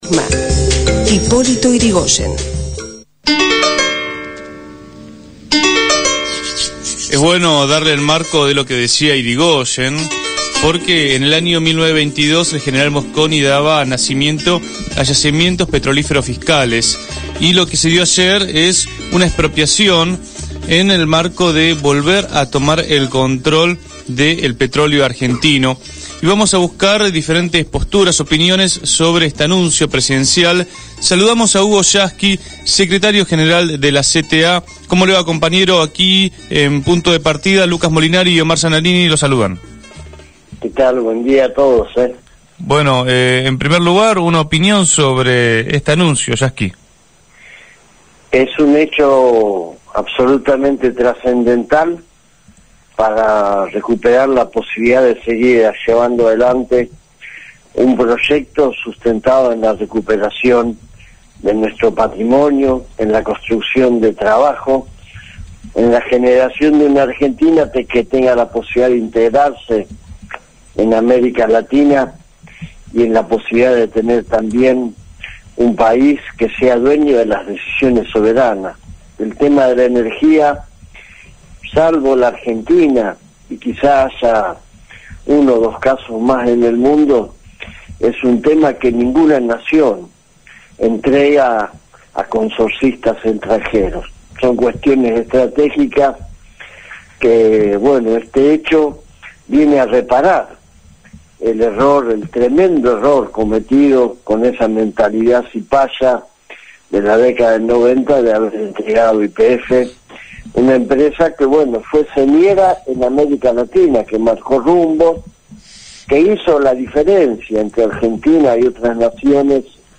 Hugo Yasky, Secretario General de la CTA, habló en Punto de Partida.